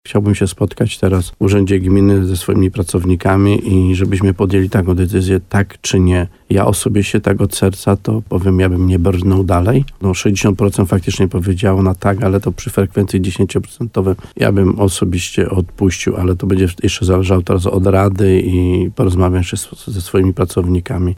W programie Słowo za Słowo na antenie RDN Nowy Sącz wójt gminy Chełmiec Stanisław Kuzak podkreślił, że teraz będą zapadały kolejne decyzje w sprawie ewentualnego wniosku do ministerstwa o zmianę statusu.